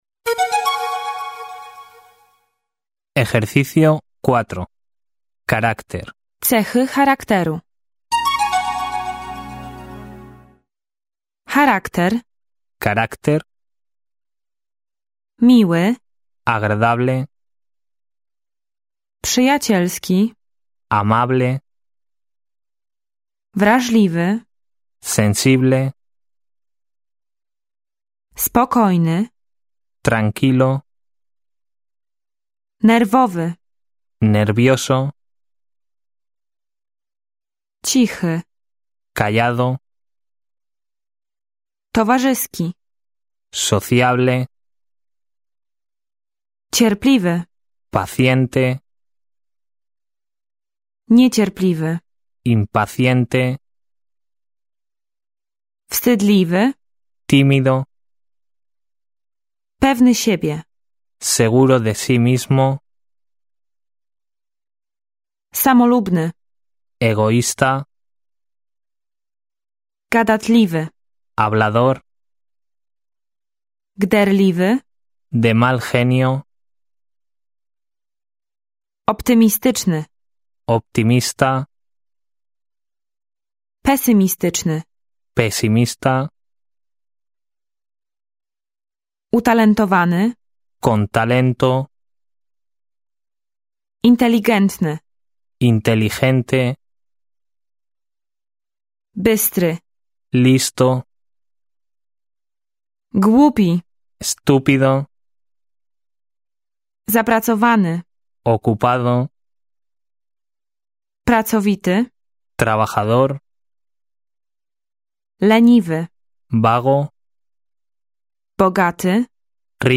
audiobook mp3 do pobrania
Audio kurs z serii Escucha & Aprende do samodzielnej nauki ze słuchu, którego celem jest opanowanie podstawowego słownictwa niezbędnego w codziennych rozmowach oraz pokonanie bariery w mówieniu i nauka tworzenia prostych zdań w języku hiszpańskim. Wszystkie słowa i wyrażenia nagrane są z tłumaczeniami, co ułatwia korzystanie z kursu oraz pozwala szybko je opanować i utrwalić, a specjalnie opracowane ćwiczenia umożliwiają naukę w dowolnym miejscu i czasie - w drodze do pracy, w podróży, w domu.